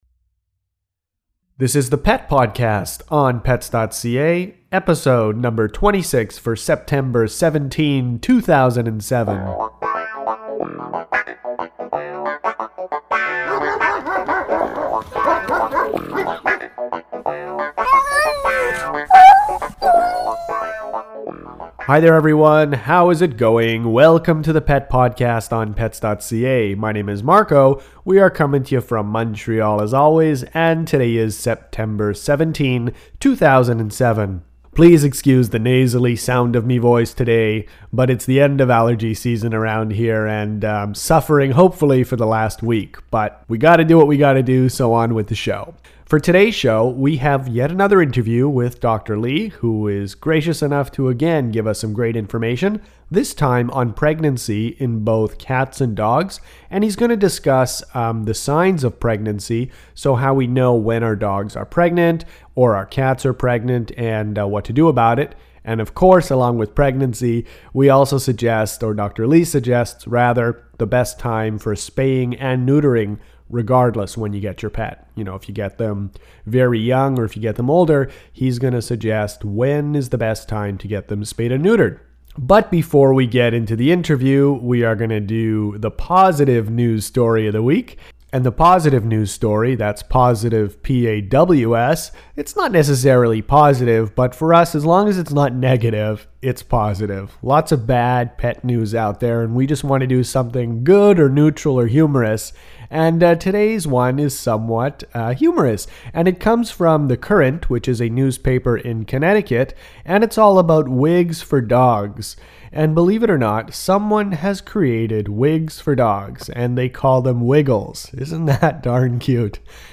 Signs of pregnancy in dogs and cats – Pet podcast #26